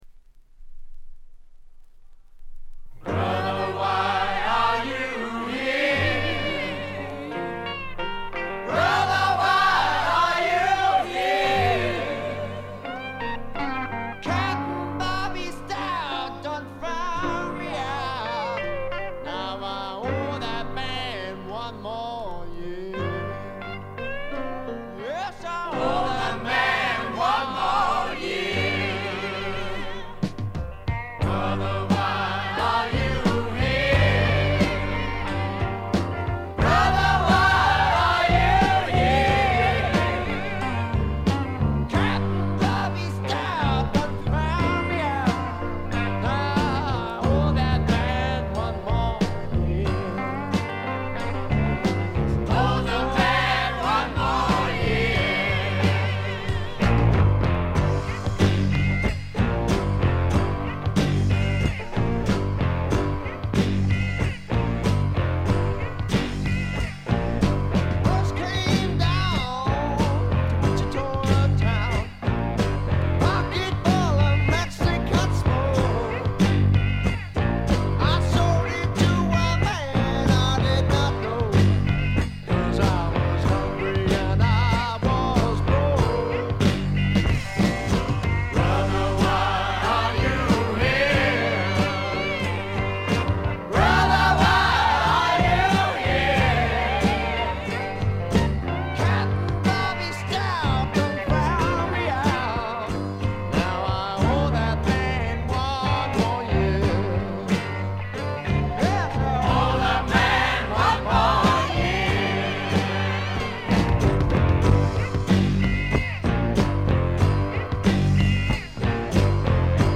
試聴曲は現品からの取り込み音源です。
Recorded at Maximum Sound, I.B.C. Studios